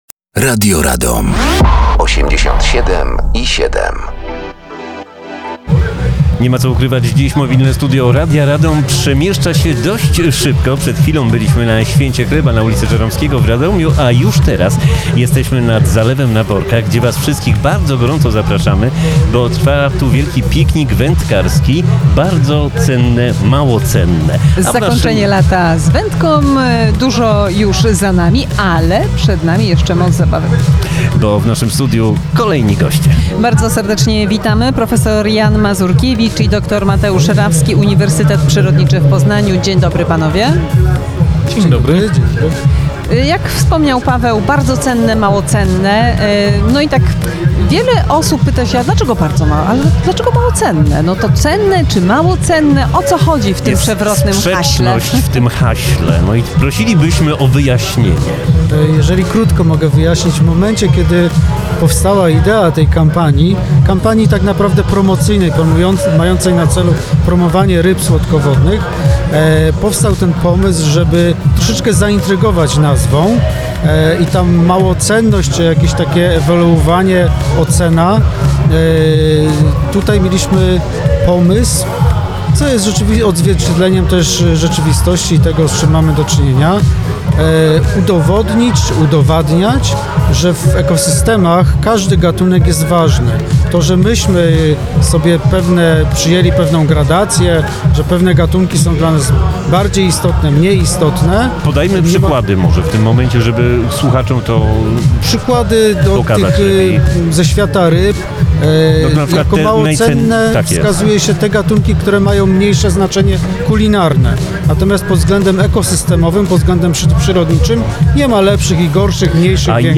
Mobilne Studio Radi Radom na Zalewem na Borkach podczas pikniku wędkarskiego Pożegnanie Lata 2025.